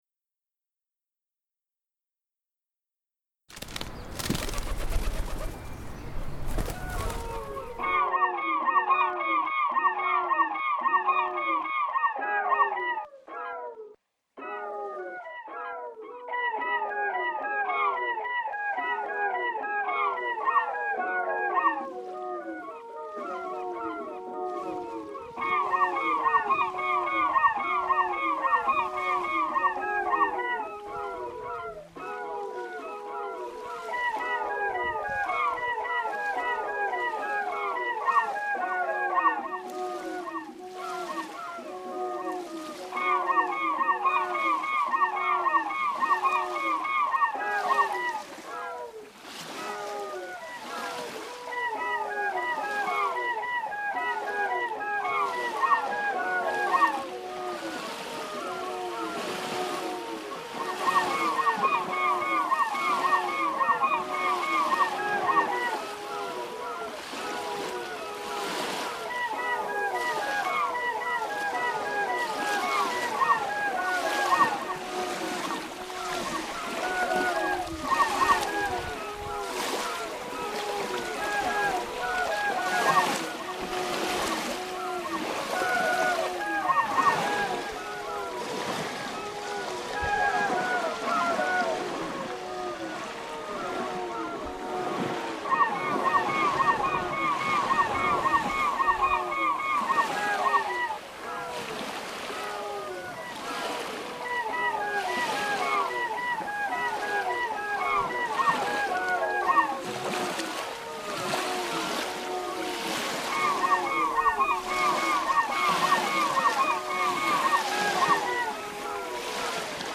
Sound files / installation
He created a playlist of their favourite songs being sung by birds which have a special personal connection or meaning.